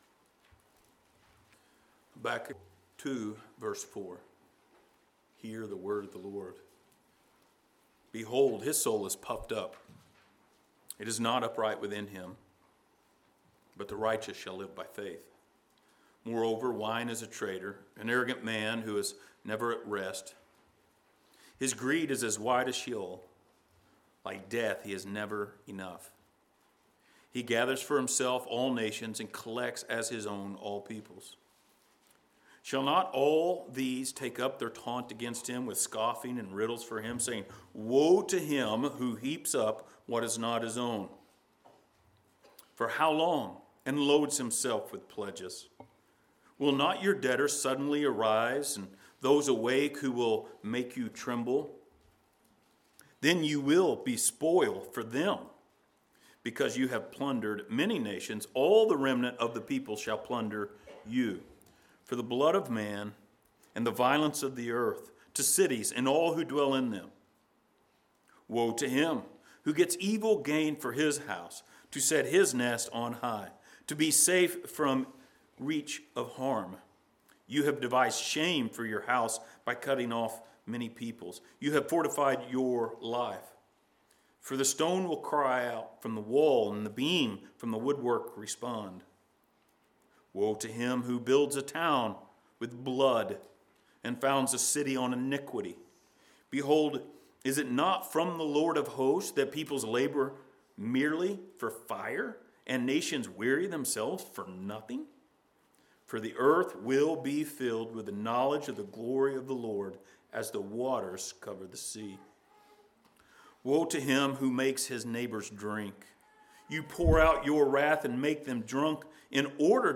Book of Habakkuk Passage: Habakkuk 2:6-20 Service Type: Sunday Morning Related Topics